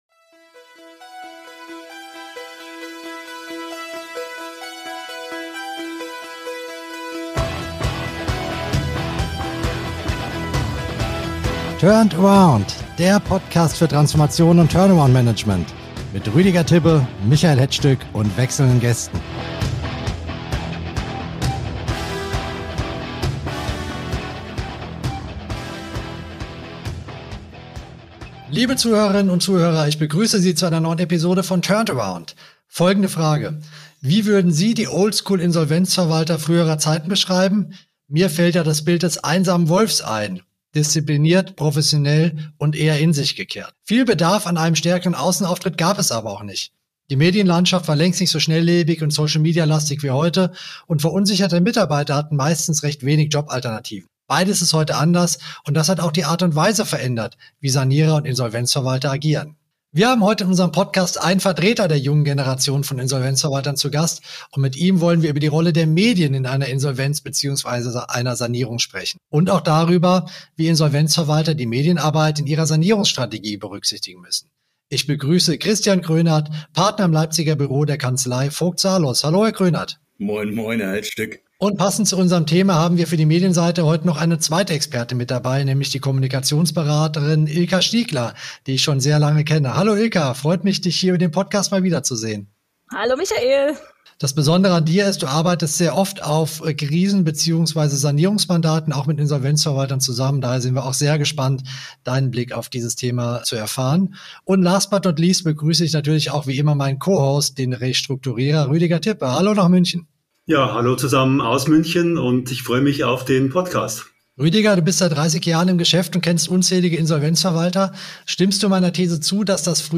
Social-Media-Kampagnen und Abwerbeversuche der Konkurrenz: Ein Insolvenzverwalter und eine Kommunikatorin erzählen, wie Medienarbeit helfen kann, Unternehmen zusammenzuhalten.